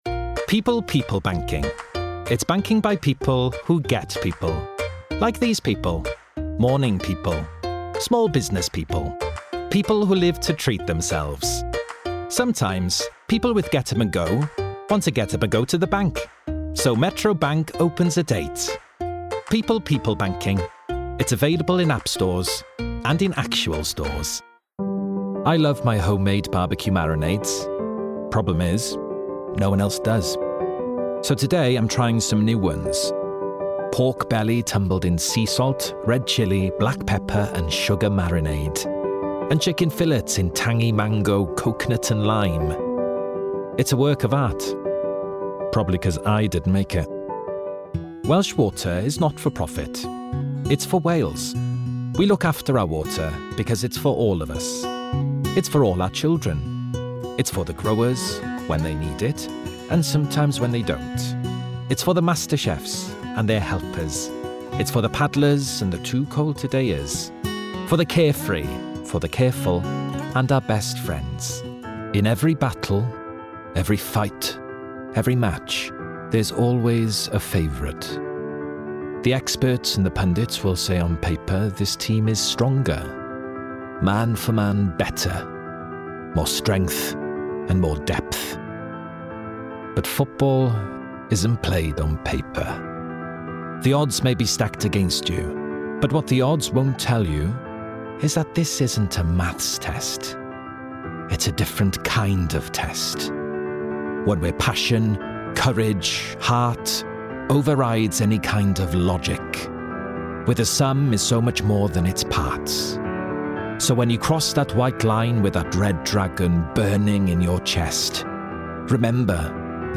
STYLE: Audio Book
20/30's Welsh, Expressive/Warm/Natural